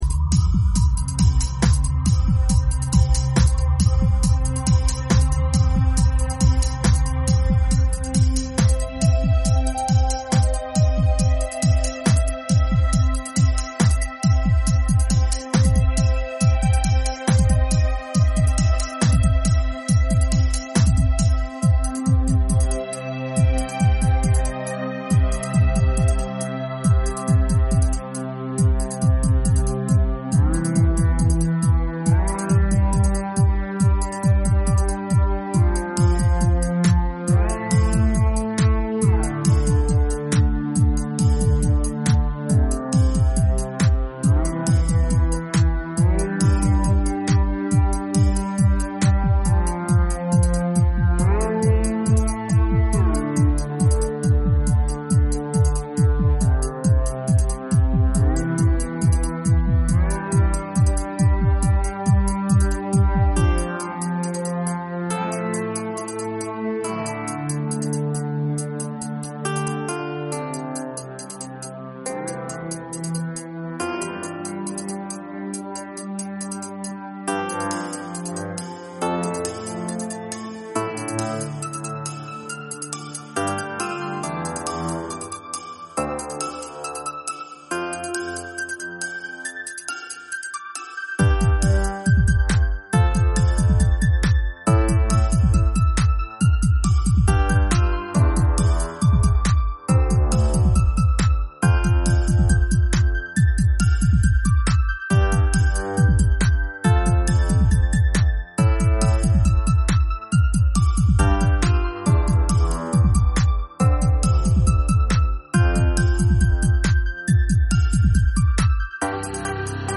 Electronic Composer create new kind of music